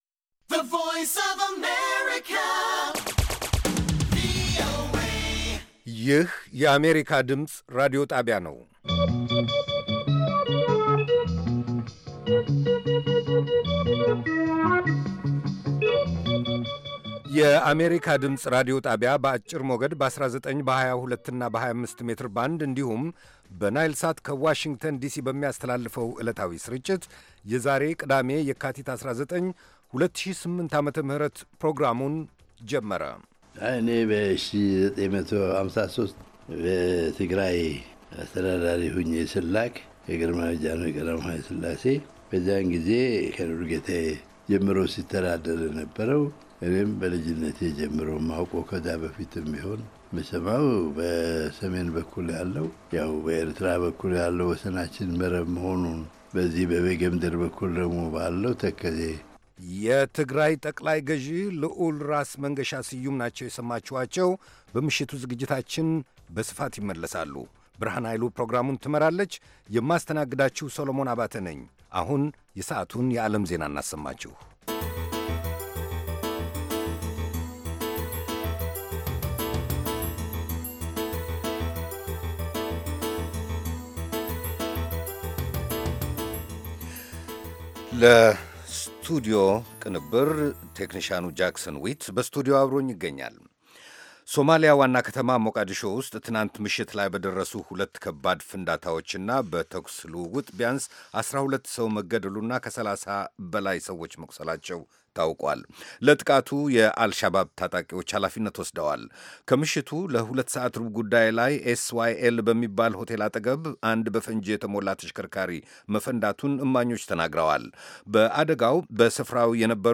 ከምሽቱ ሦስት ሰዓት የአማርኛ ዜና
ቪኦኤ በየዕለቱ ከምሽቱ 3 ሰዓት በኢትዮጵያ ኣቆጣጠር ጀምሮ በአማርኛ፣ በአጭር ሞገድ 22፣ 25 እና 31 ሜትር ባንድ የ60 ደቂቃ ሥርጭቱ ዜና፣ አበይት ዜናዎች ትንታኔና ሌሎችም ወቅታዊ መረጃዎችን የያዙ ፕሮግራሞች ያስተላልፋል። ቅዳሜ፡- ከዚህም ከዚያም፤ የሙዚቃ ቃና